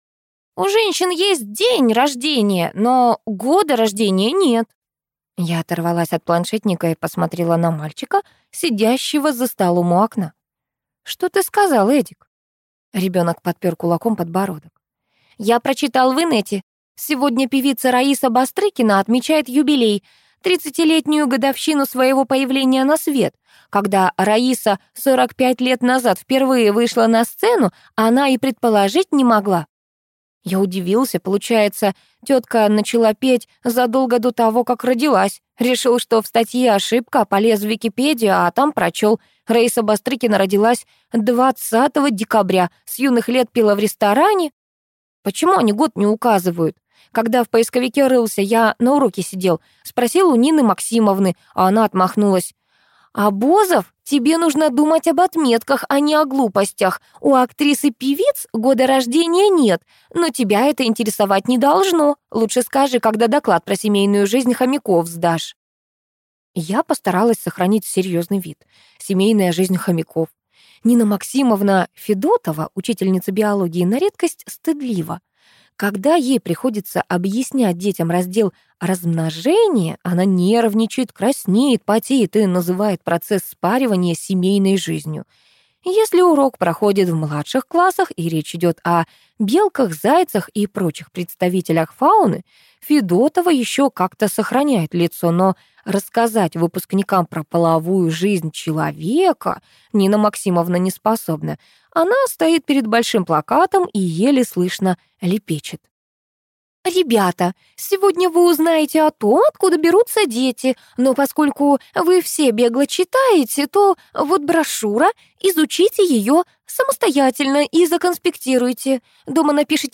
Аудиокнига Магия госпожи Метелицы | Библиотека аудиокниг